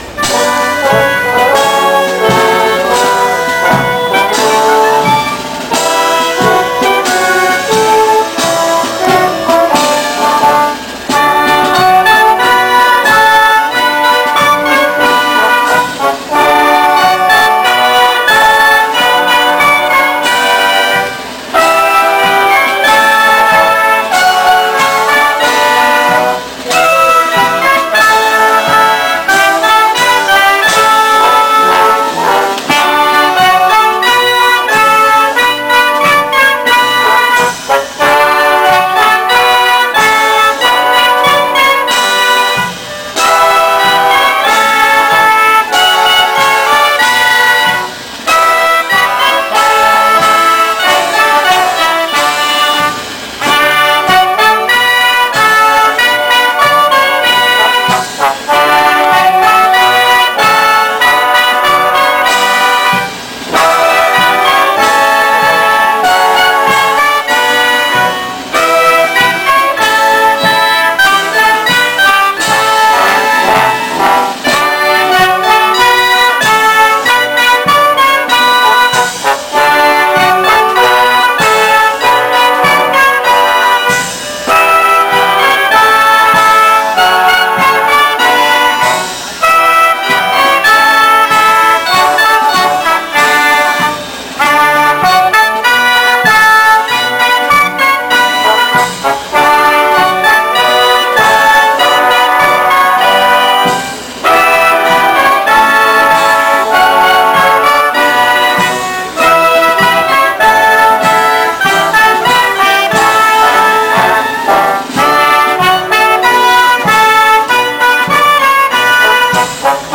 na cerimônia realizada na Praça da República, na manhã de 26 de janeiro de 2008
feita pela Banda de Música da 1ª Brigada de Artilharia Antiaérea